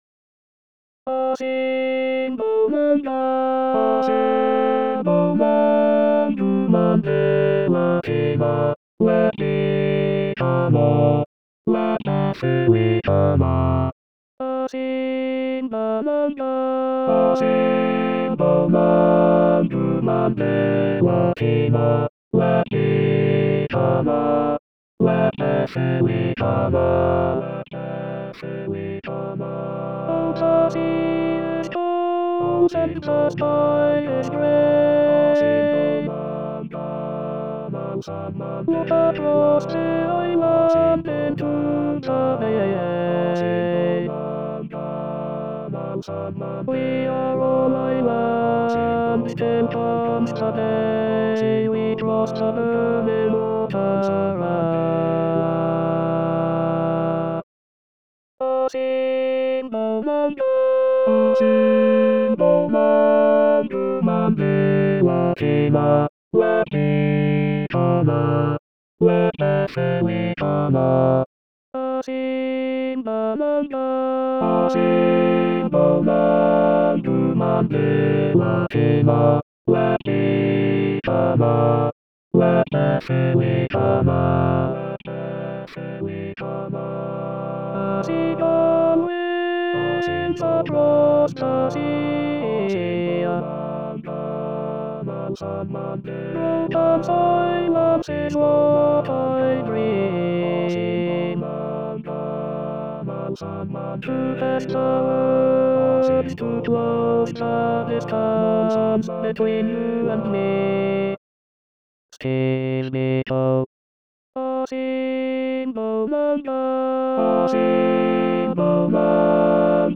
Asimbonanga Tutti.mp3